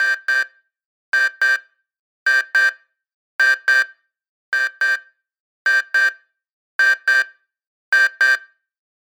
Enemy SFX